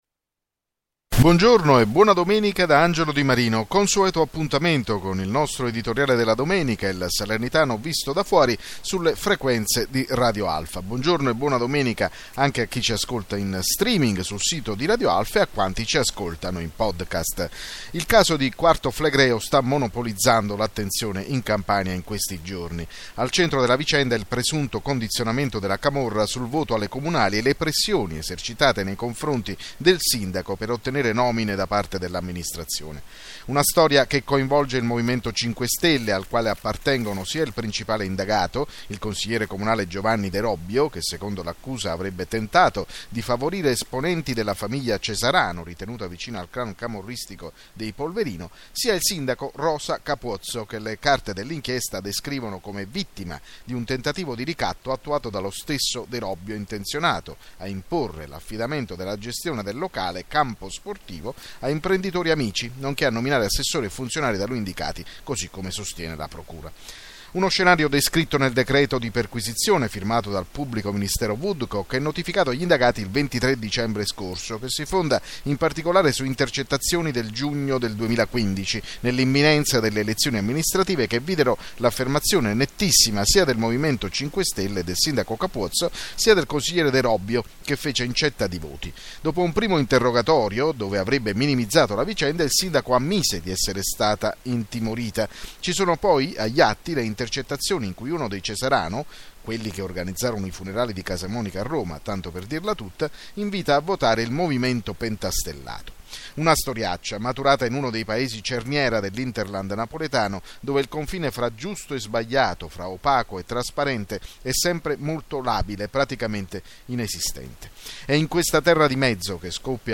Editoriale andato in onda su Radio Alfa il 10 gennaio 2016